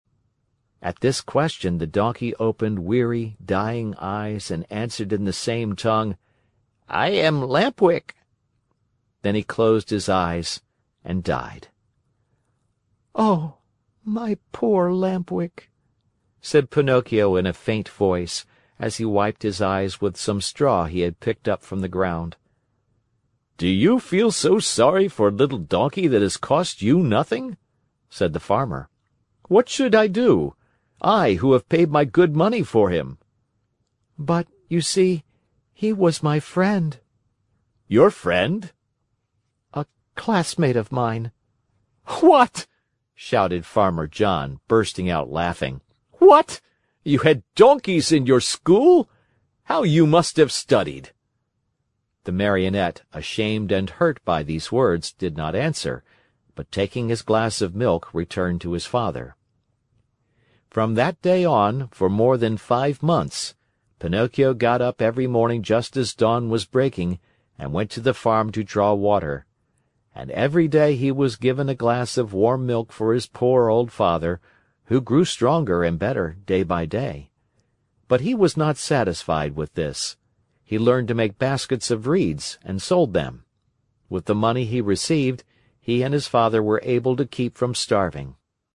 在线英语听力室木偶奇遇记 第162期:匹诺曹梦想成真(8)的听力文件下载,《木偶奇遇记》是双语童话故事的有声读物，包含中英字幕以及英语听力MP3,是听故事学英语的极好素材。